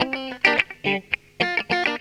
GTR 68.wav